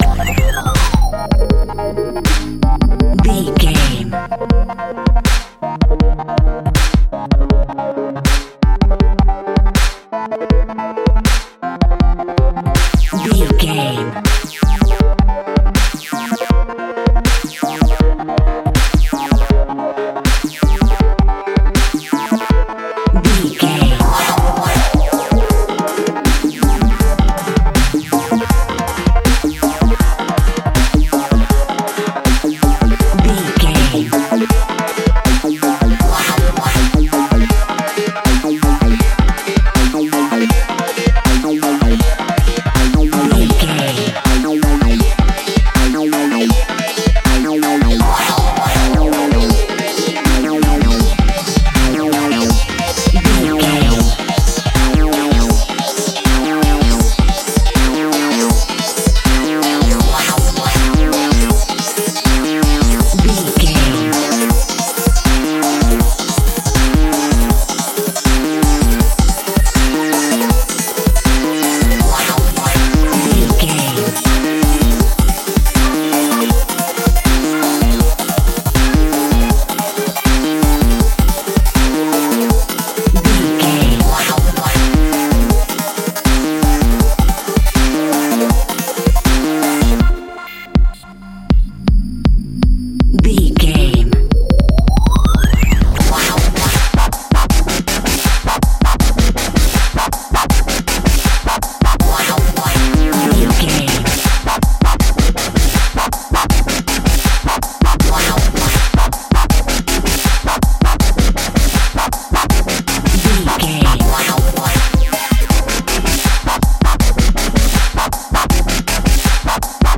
Epic / Action
Aeolian/Minor
Fast
aggressive
dark
intense
dramatic
energetic
driving
frantic
synthesiser
drum machine
breakbeat
synth leads
synth bass